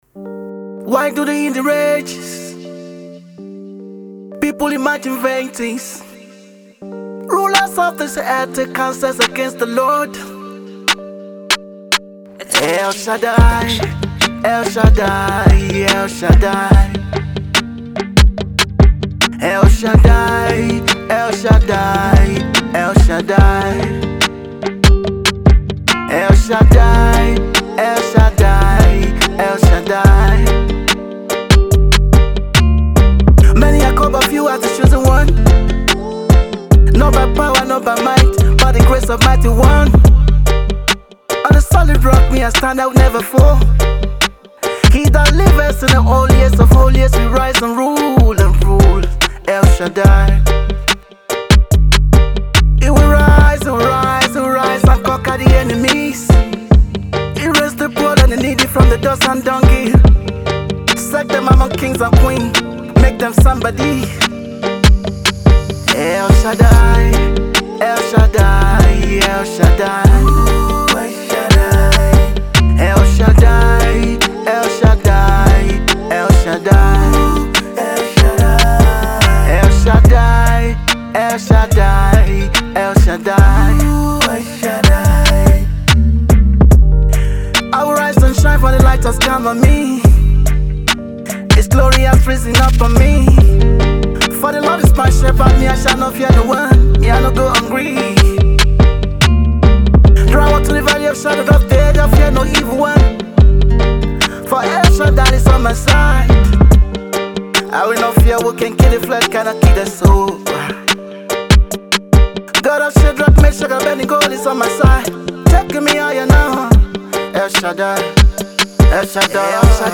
” each offering a unique mix of Afro-fusion beats and
motivational reggae melodies.